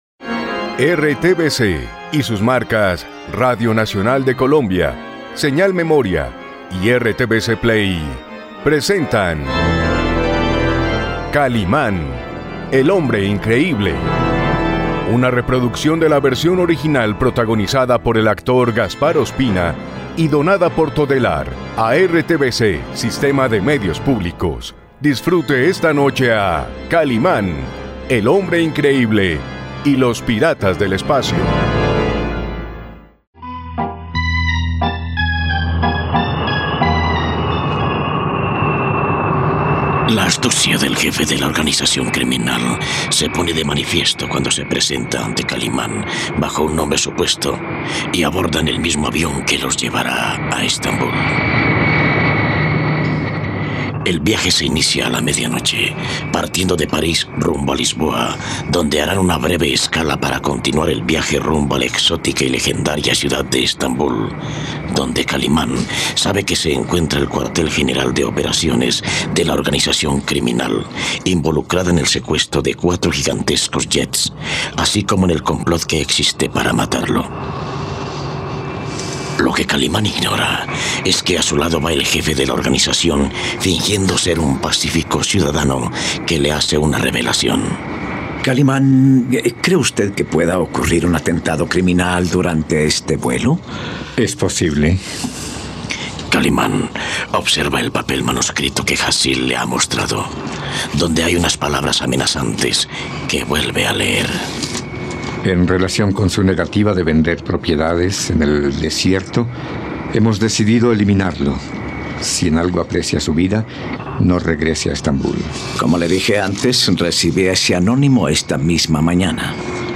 ..Disfruta de una nueva aventura junto al hombre increíble, en RTVCPlay puedes disfrutar de la radionovela completa de 'Kalimán y los piratas del espacio'.